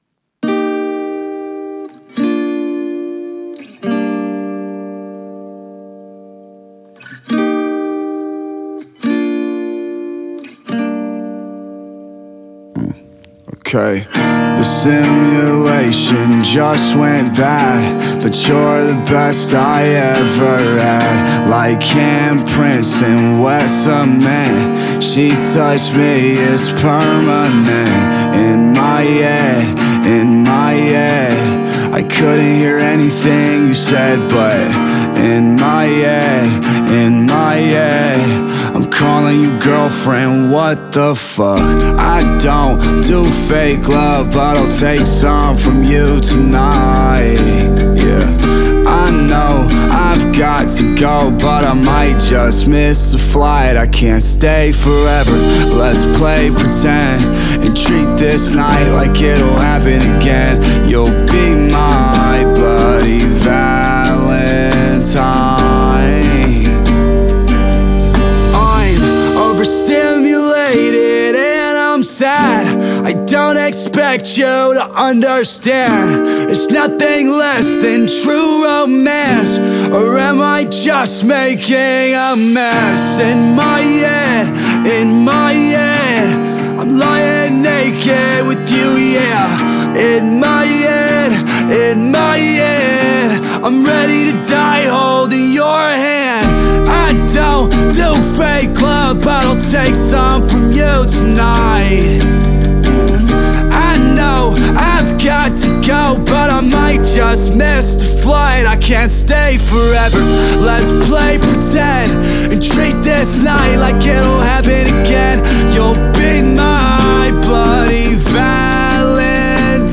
acoustic version of the single